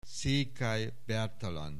Aussprache Aussprache
SZEKELYBERTALAN.wav